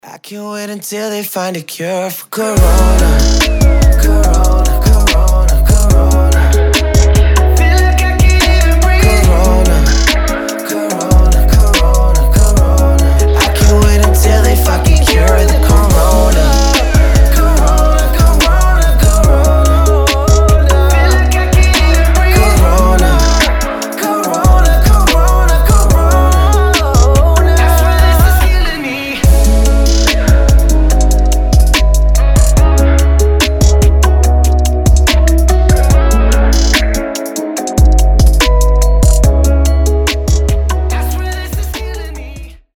мужской голос
Хип-хоп
грустные